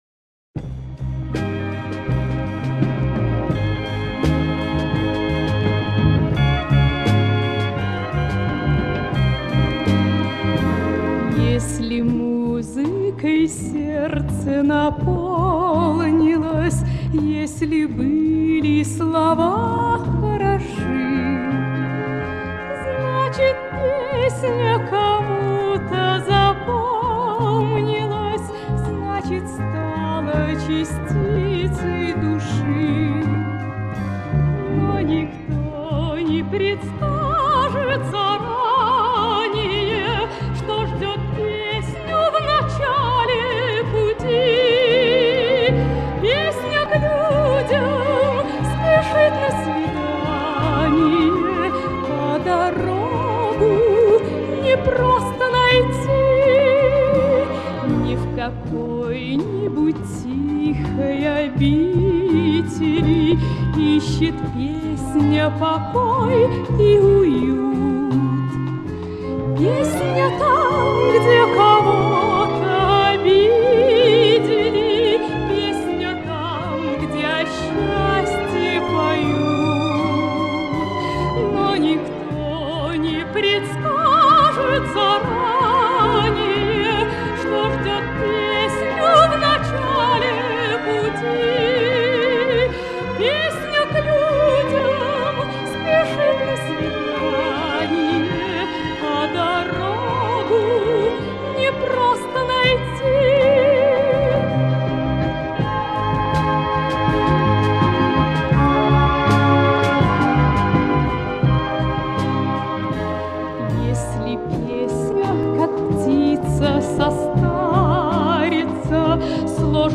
Качество записи улучшено.